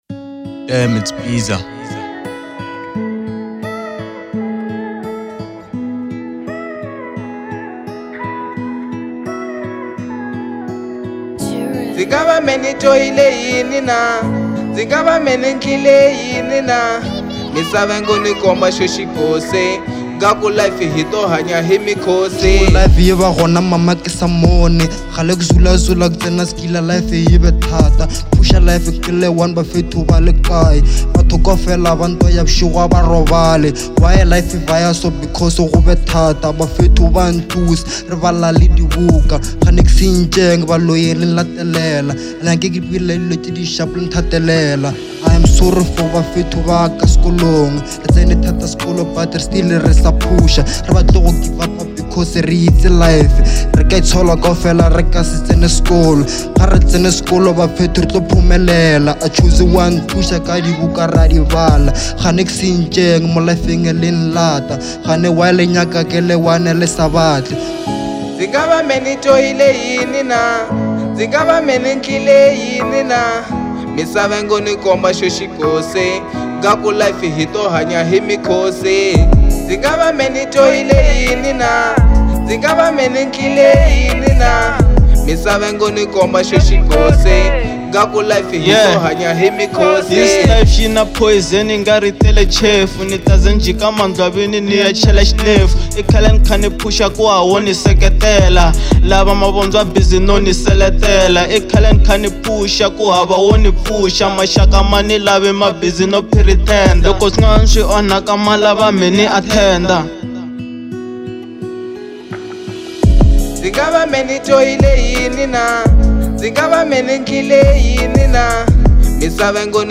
02:43 Genre : Hip Hop Size